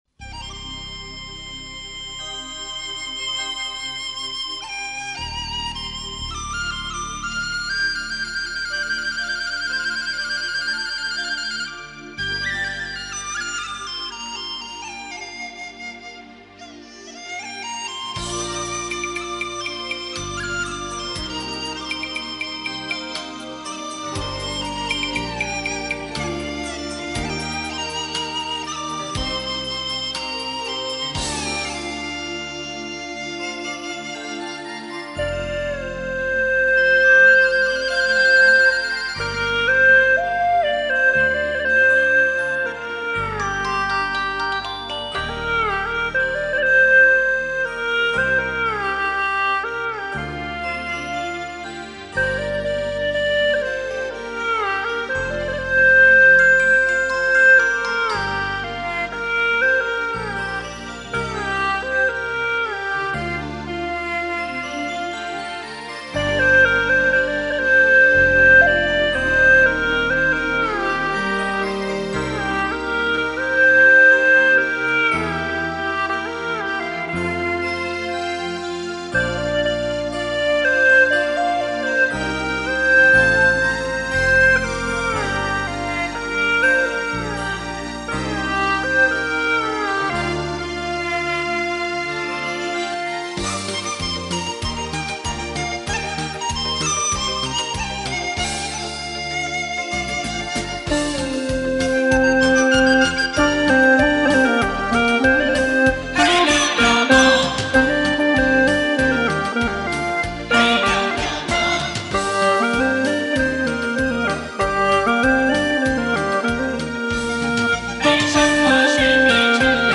调式 : 降B 曲类 : 民族 此曲暂无教学 点击下载 好歌才， 只有三姐唱得来， 心想与姐唱几句， 不知金口开不开。